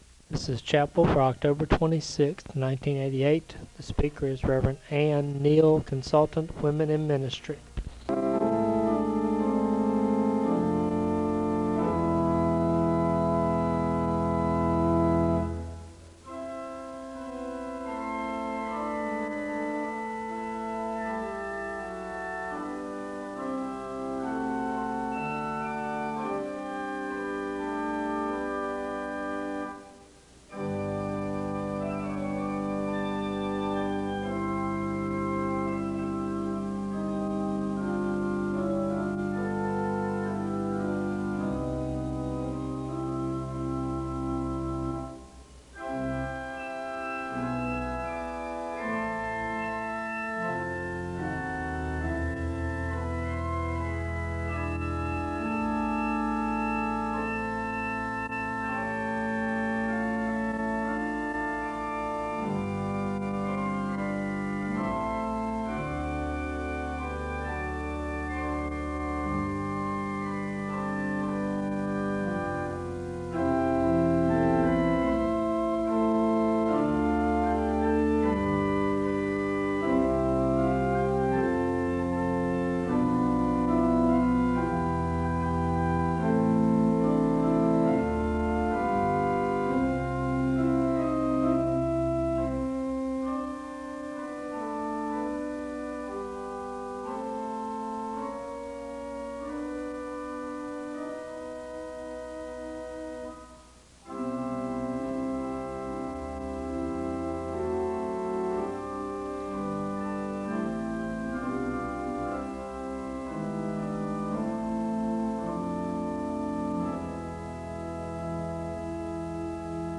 A hymn is played (0:08-6:54). Luke 1:39-56 is the Scripture reading of the day (6:55-9:39)....
A word of prayer is given (11:06-12:26).